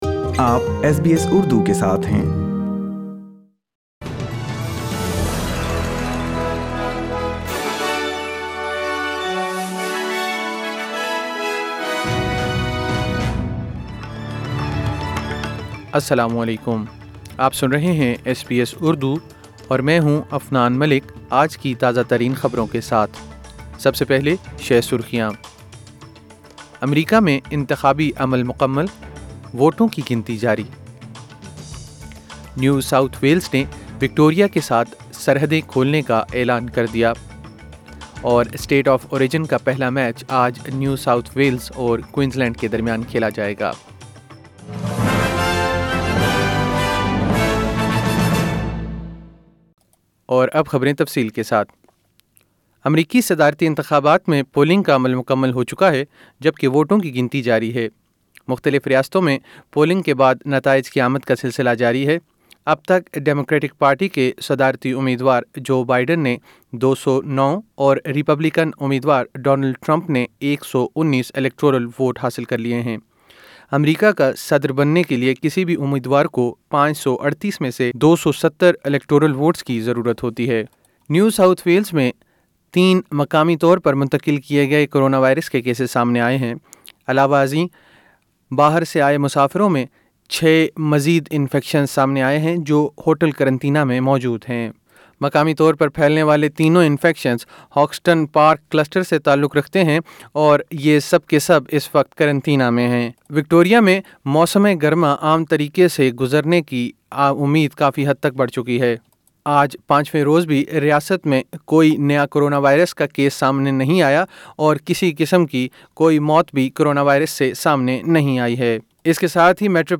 ایس بی ایس اردو خبریں 04 نومبر 2020